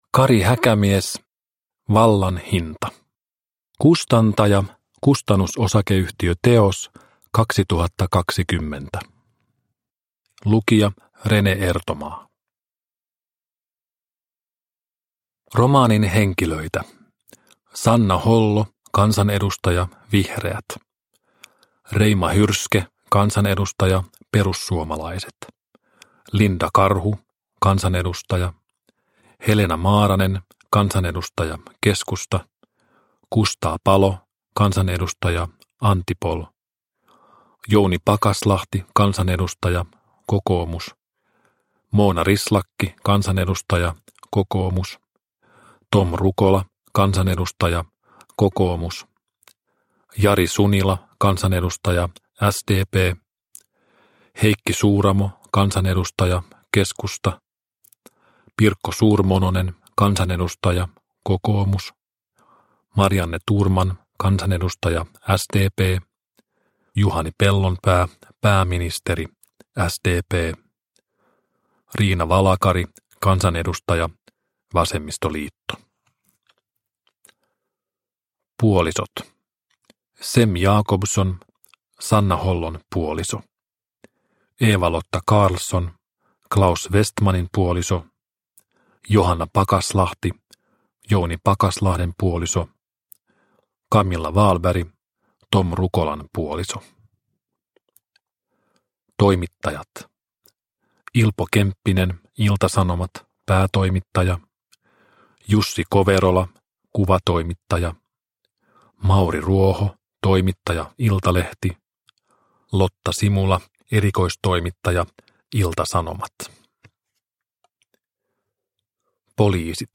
Vallan hinta (ljudbok) av Kari Häkämies